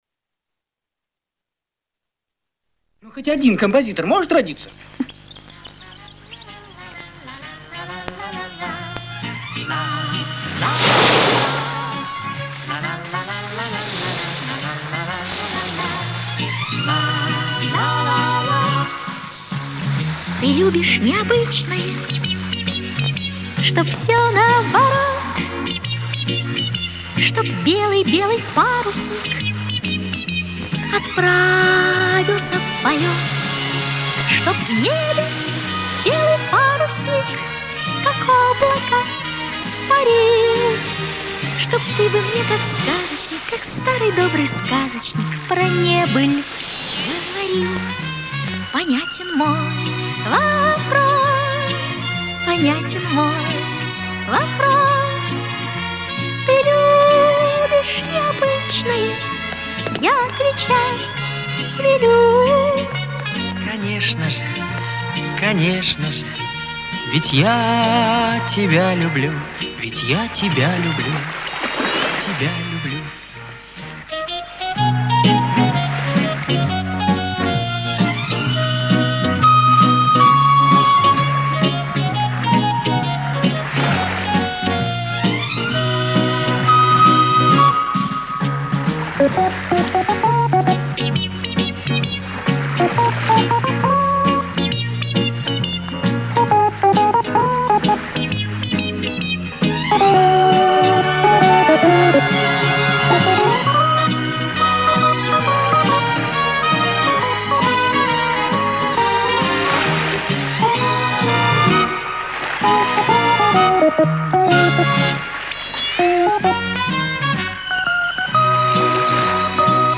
Музыка / Музыка кино, спектаклей / Музыка к кинофильмам
Режим: Mono